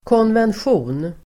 Uttal: [kånvensj'o:n]